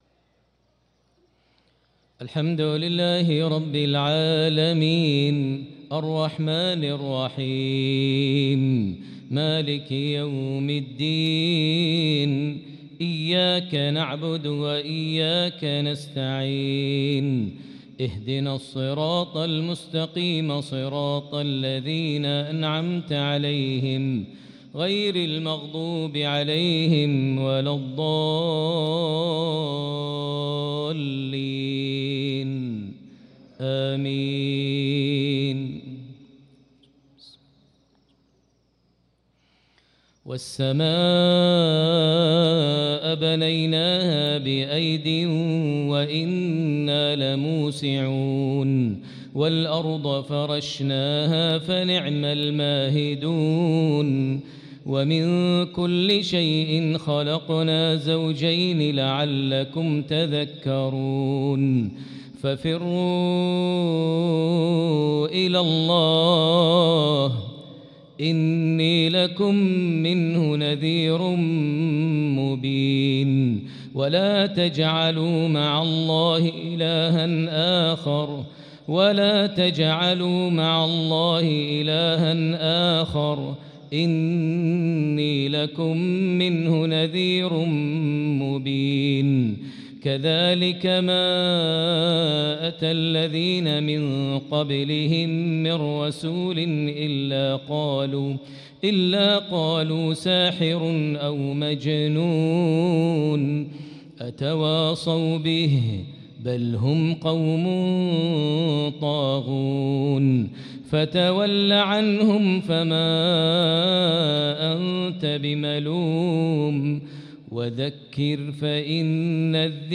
صلاة المغرب للقارئ ماهر المعيقلي 8 شعبان 1445 هـ
تِلَاوَات الْحَرَمَيْن .